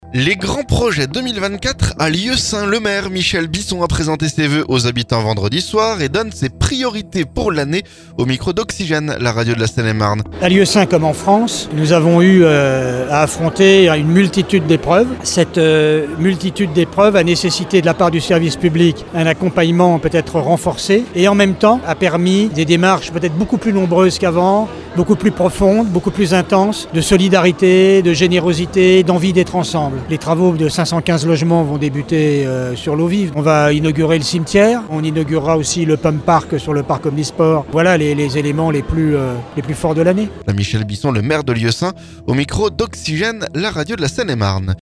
Le maire Michel Bisson a présenté ses vœux aux habitants vendredi soir. Et donne ses priorités pour l'année au micro Oxygène, la radio de la Seine-et-Marne.